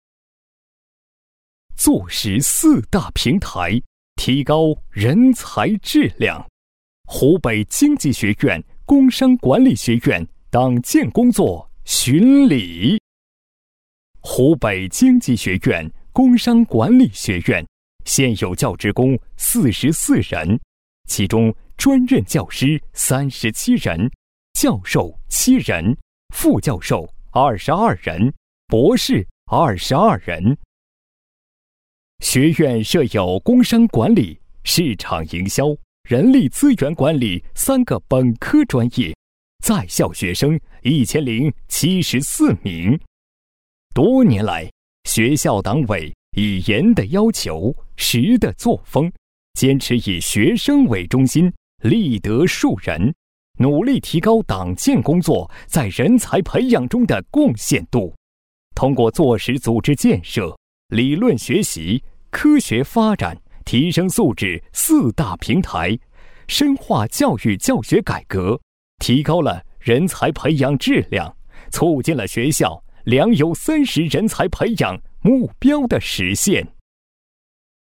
男声配音